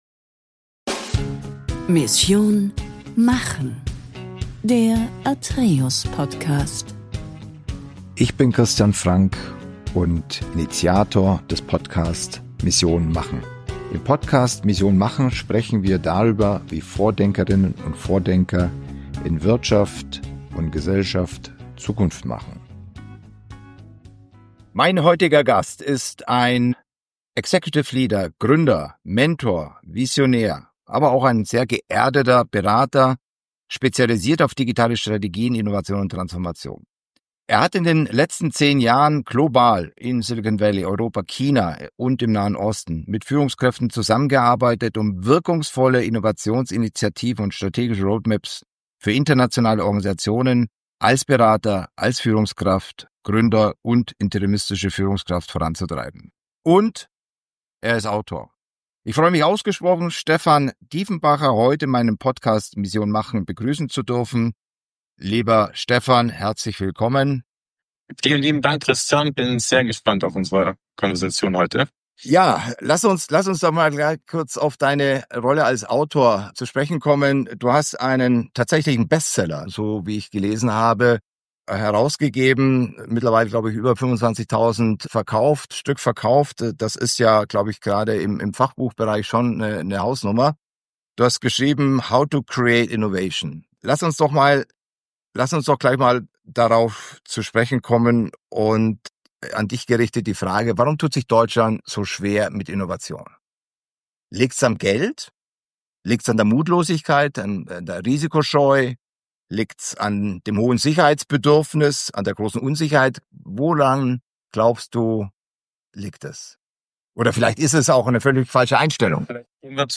(Bitte entschuldigen Sie die eingeschränkte Tonqualität dieser Episode.